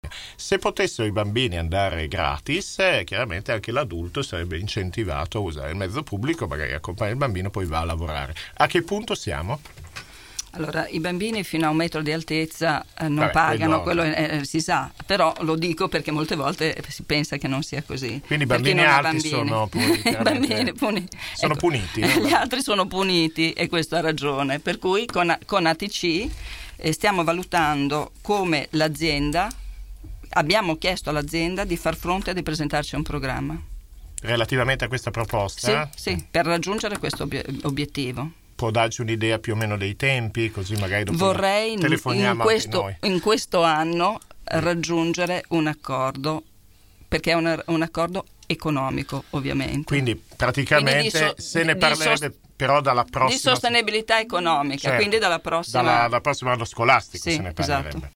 “C’è l’obiettivo di aumentarle perché i mezzi pubblici possano viaggiare più velocemente” ha fatto sapere questa mattina durante il microfono aperto nei nostri studi l’assessore al Traffico e alla Mobilità, Simonetta Saliera.